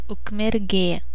pronunciation; previously Vilkmergė; Polish: Wiłkomierz) is a city in Vilnius County, Lithuania, located 78 km (48 mi) northwest of Vilnius.[1] It is the administrative center of the Ukmergė District Municipality.[2]